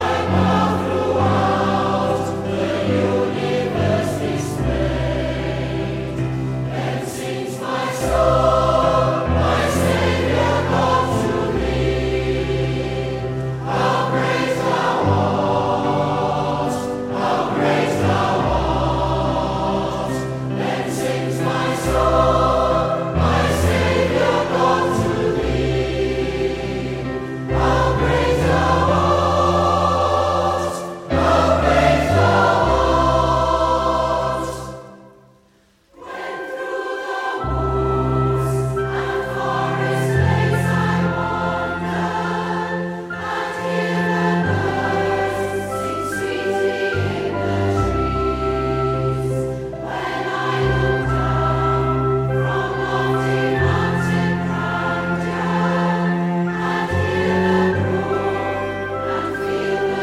Praise & Worship